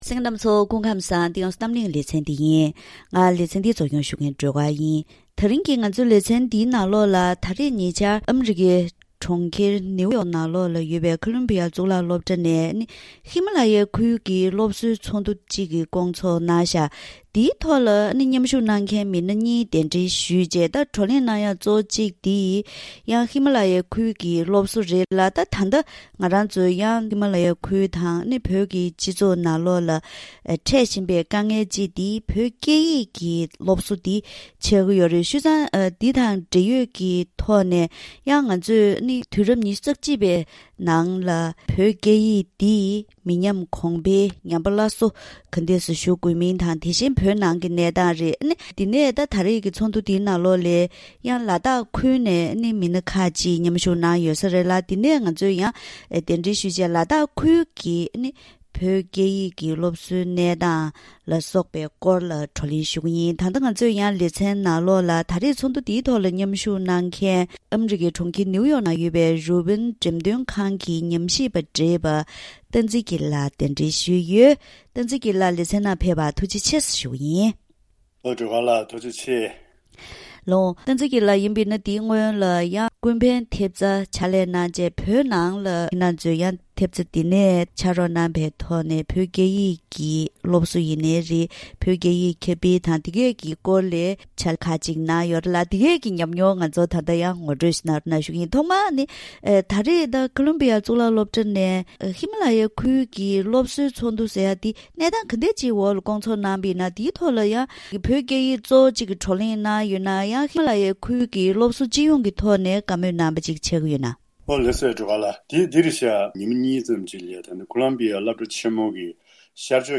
དེ་བཞིན་ལ་དྭགས་ཁུལ་གྱི་བོད་སྐད་ཡིག་སློབ་གསོ་སོགས་ཀྱི་ཐོག་འབྲེལ་ཡོད་མི་སྣ་དང་བཀའ་མོལ་ཞུས་པ་ཞིག་གསན་རོགས་གནང་།